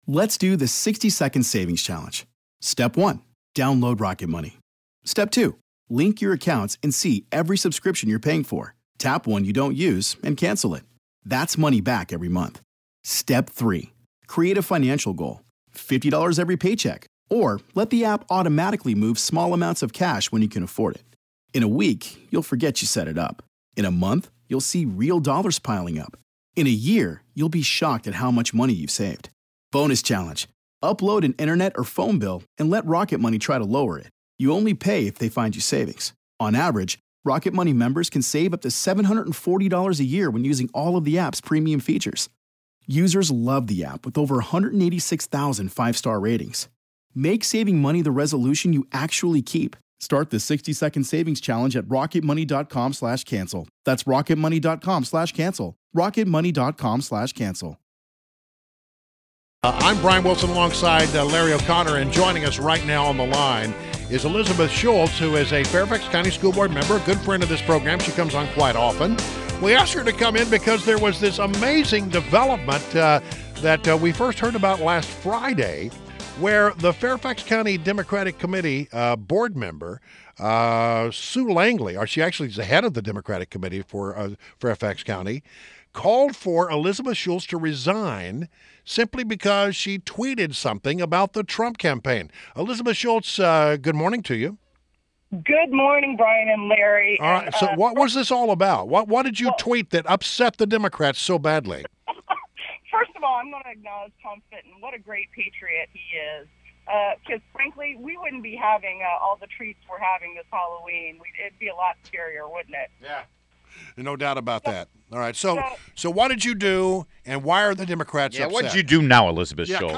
WMAL Interview - ELIZABETH SCHULTZ - 10.31.16
INTERVIEW — ELIZABETH SCHULTZ – Fairfax County Public School board member